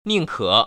[nìngkě] 닝커